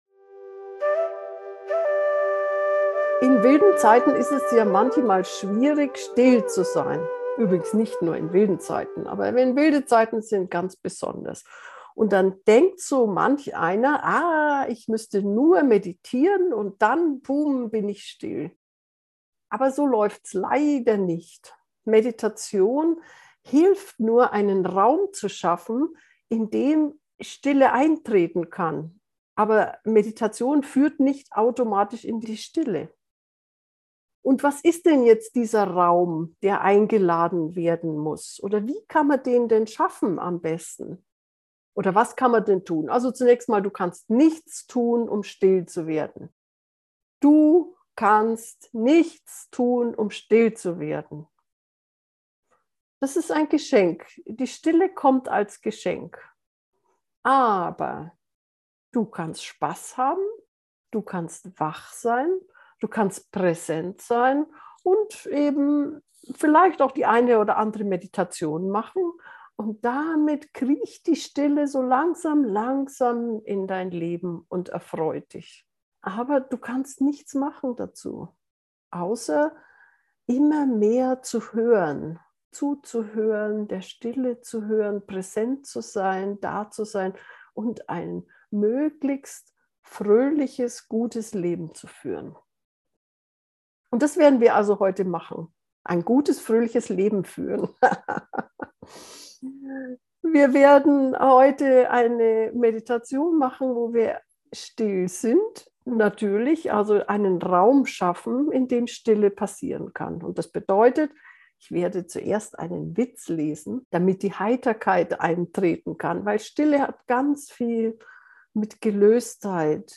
sehnsucht-stille-gefuehrte-meditation